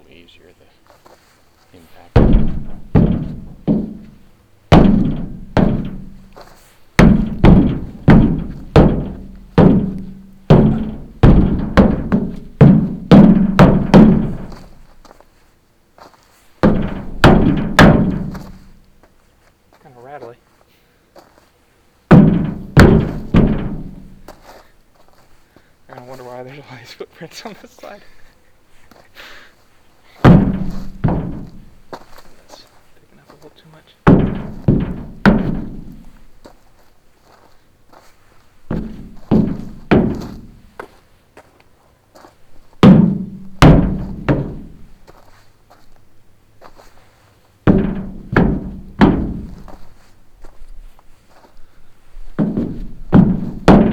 Index of /files/mediasource/sounds/footsteps/metal
footsteps_dumpster1_raw.wav